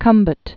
(kŭmbət), Gulf of or Gulf of Cam·bay (kăm-bā)